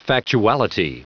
Prononciation du mot factuality en anglais (fichier audio)
Prononciation du mot : factuality